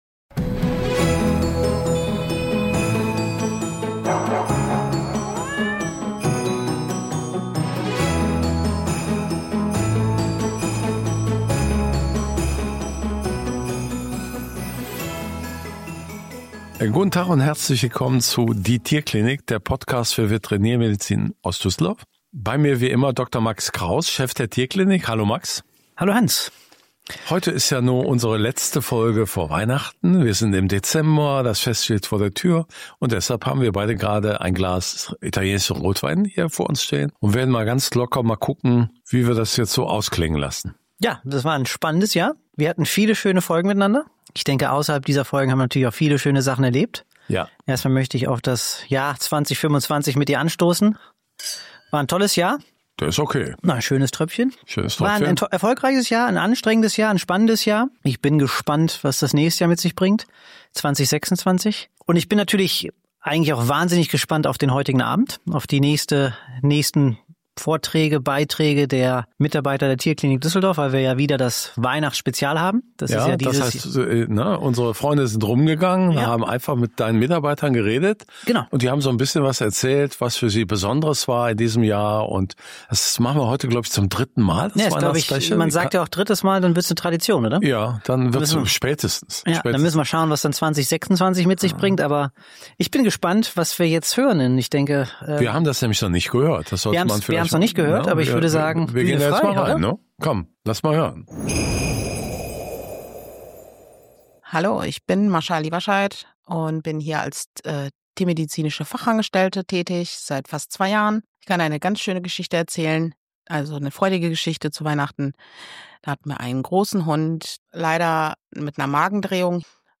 Kolleginnen und Kollegen aus der Tierklinik Düsseldorf erzählen ihre ganz persönlichen Geschichten aus dem Klinikalltag: berührend, lustig, manchmal absurd – und immer ehrlich. Vom geretteten Hund nach einer lebensbedrohlichen Magendrehung über legendäre Telefonate mit besorgten Tierhaltern bis hin zu chirurgischen Anekdoten, Sprachverwirrungen und einer Fototapete, die plötzlich zum Teamprojekt wird.
Ein Weihnachtsspecial voller Lachen, Wärme und Dankbarkeit – für Tiere, Menschen und ein Jahr voller besonderer Momente.